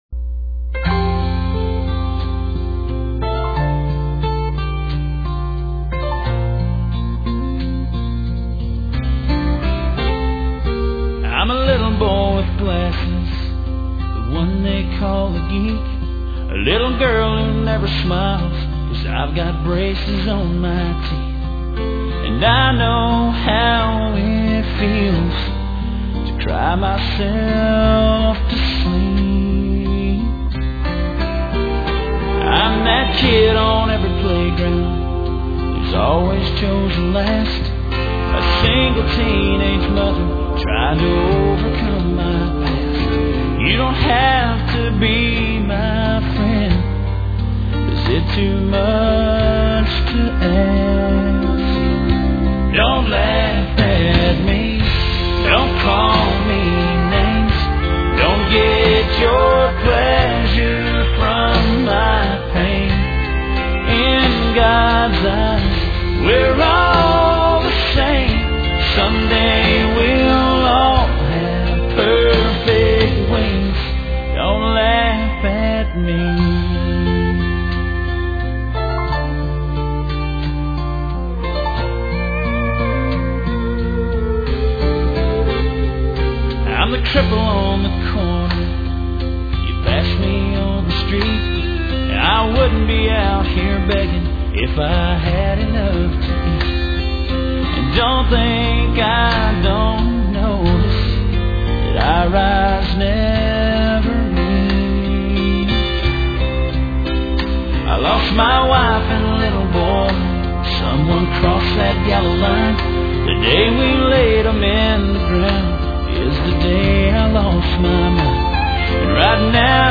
**Full Length Country Wavs**